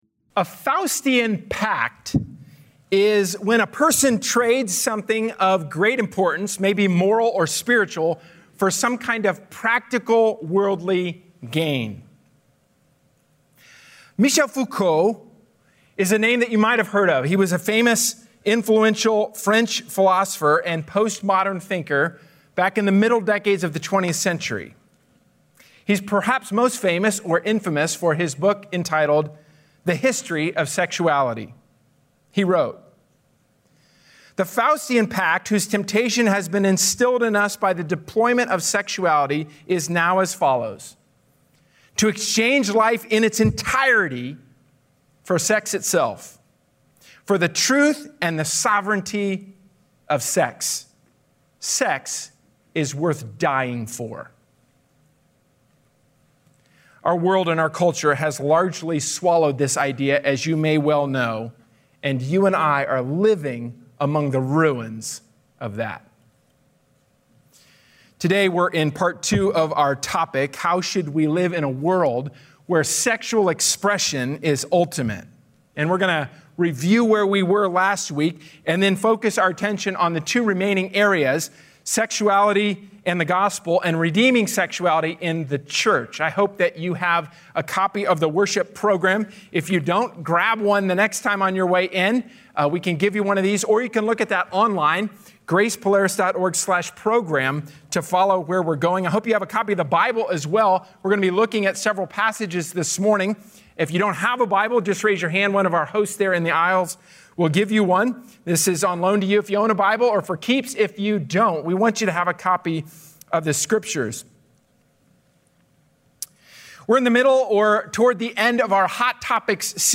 A sermon from the series "Hot Topics."